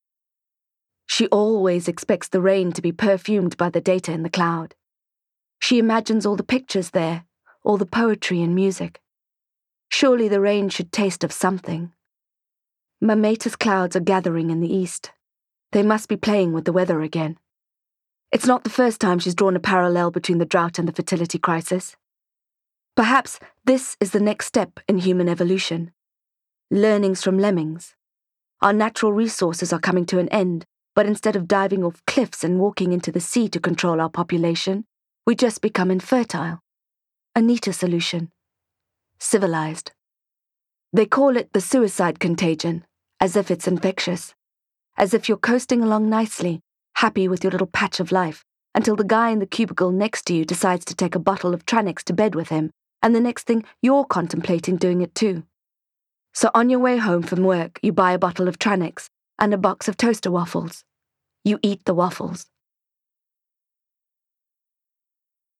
Why You Were Taken Audiobook Tour